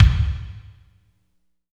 30.09 KICK.wav